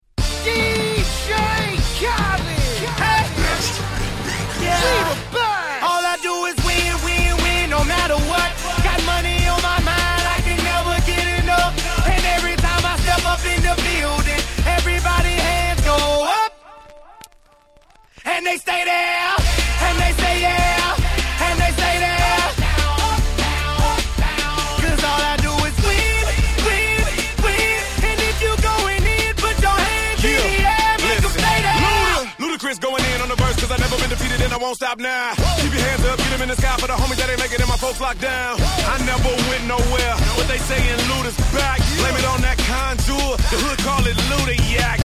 10' Super Hit Hip Hop !!